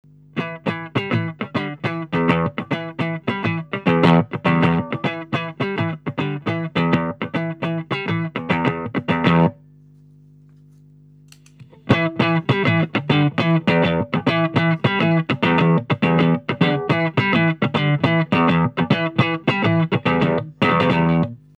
-Le compresseur : Son rôle est d’agir sur le volume sonore pour rendre un jeu homogène (réhaussement des sons faibles et réduction des sons forts).